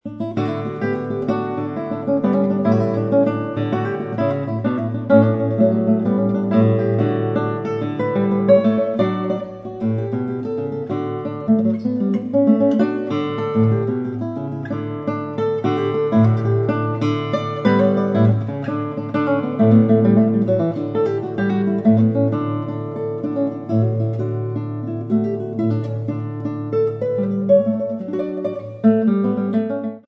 Free Classical Guitar MP3 excerpt of Sanz's Canarios from the CD Angelica